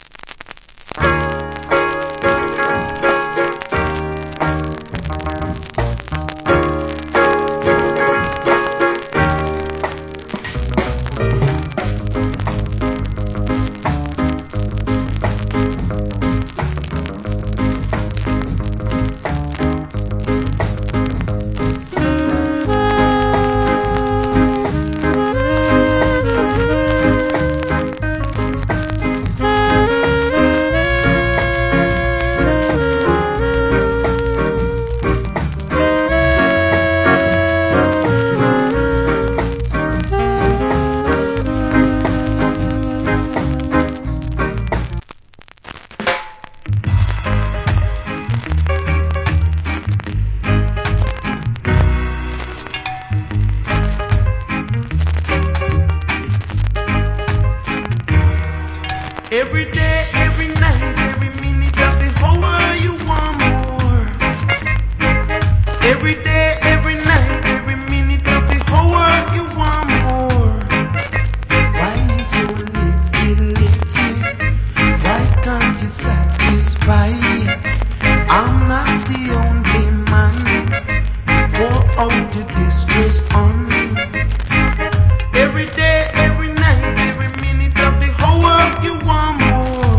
Great Rock Steady Inst.